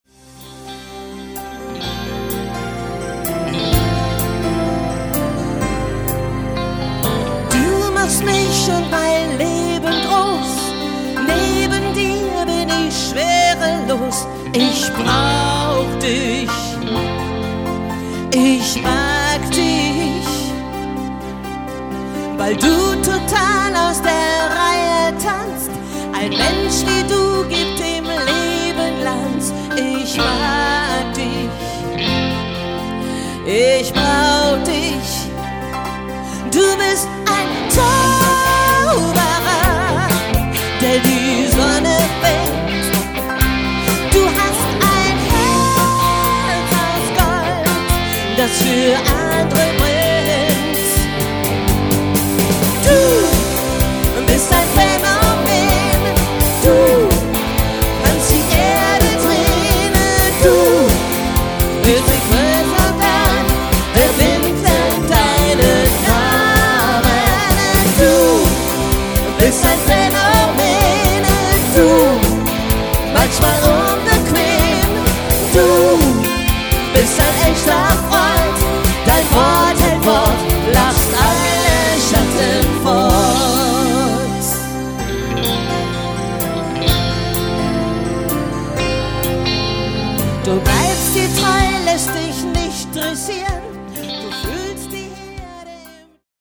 • Allround Partyband